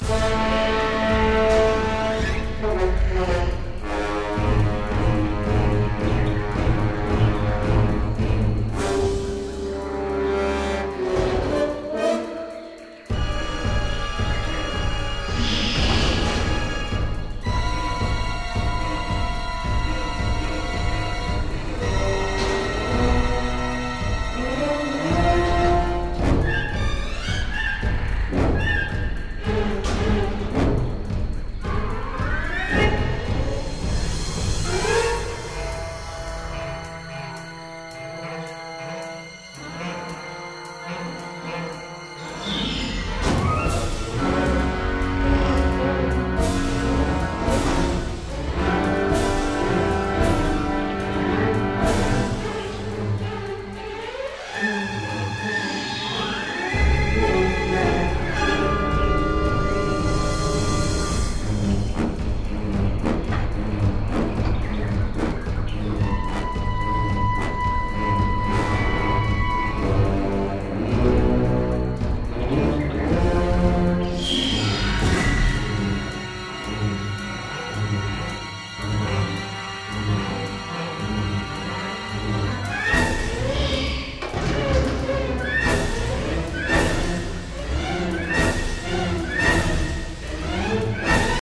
Low quality samples from the game XA music files: